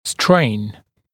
[streɪn][стрэйн]напряжение, нагрузка, натяжение, растяжение